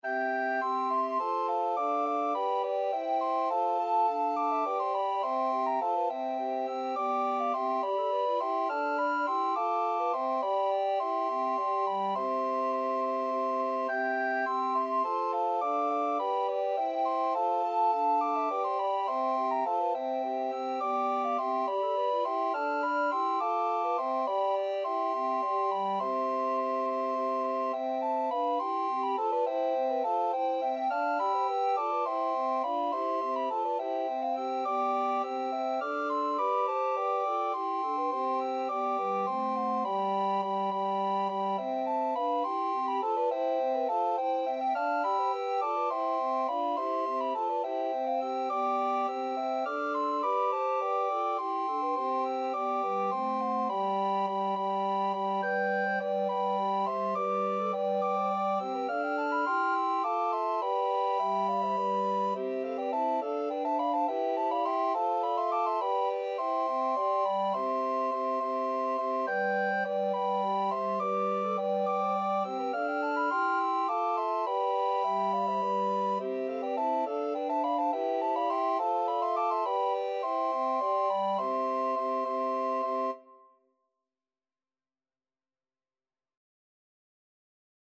6/8 (View more 6/8 Music)
Classical (View more Classical Recorder Ensemble Music)